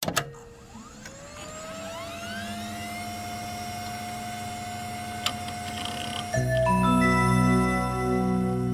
contribs)Fadeout at the end, instead of abrupt ending